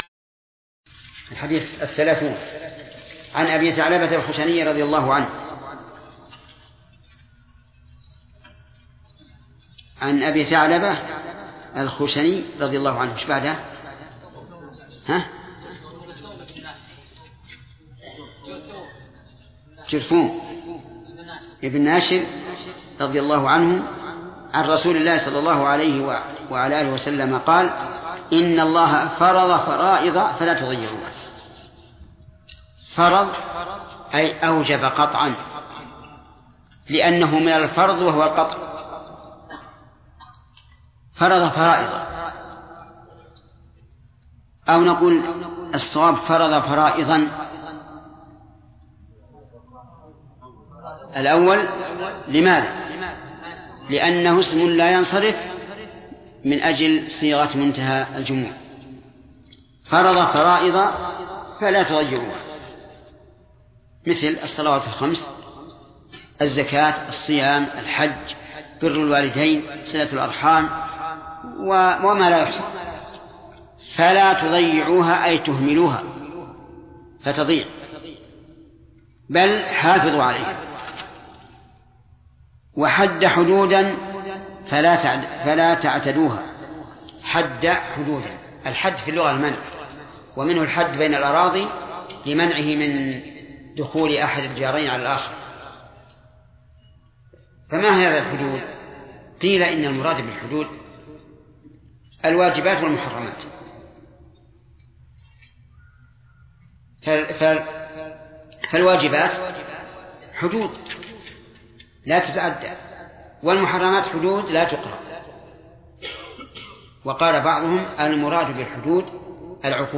الدرس الثالث والعشرون : من قوله: الحديث الثلاثون، إلى: نهاية الحديث الحادي والثلاثون.